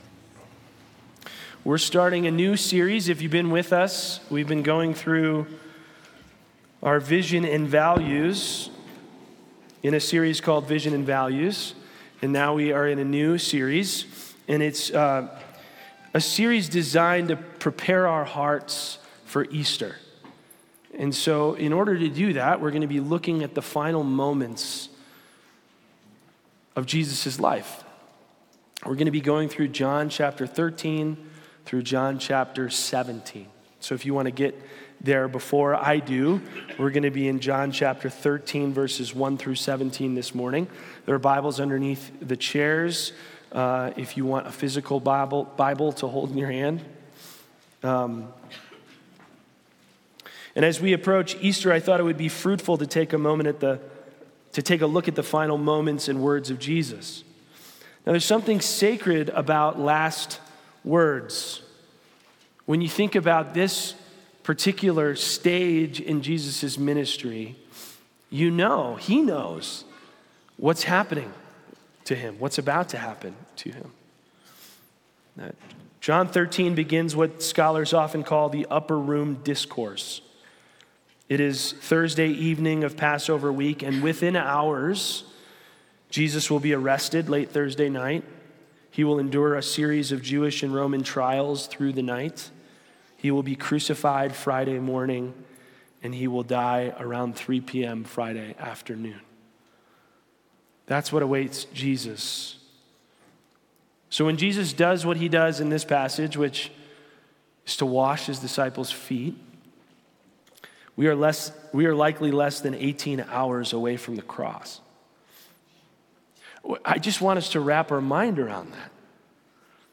Sermons | Anchor Way Church